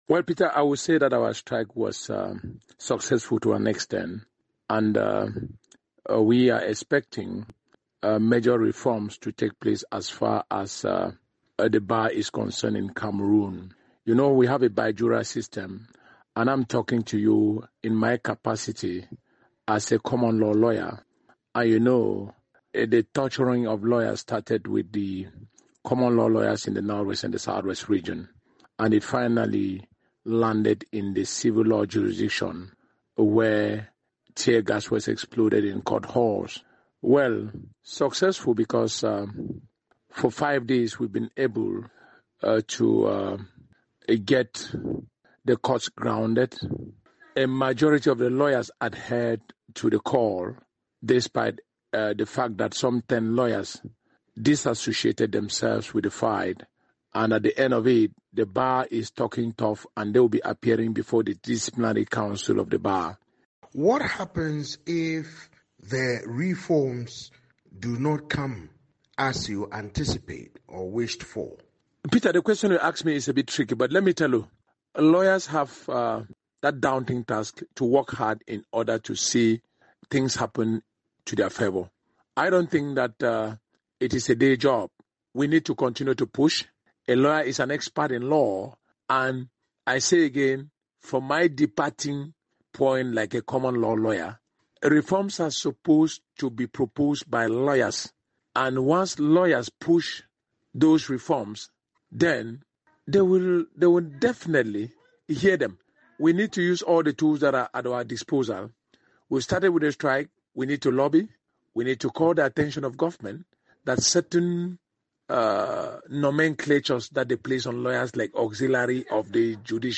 spoke to attorney